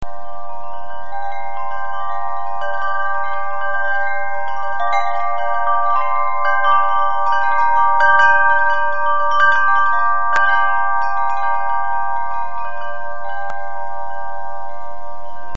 Eine Serie von Windspielen mit gleicher Tonfolge in verschiedenen Größen und entsprechenden Tonlagen.
Jeweils in pentatonischer Stimmung.
Erde (Gesamtlänge 94 cm)
Klangbeispiel Windspiel Erde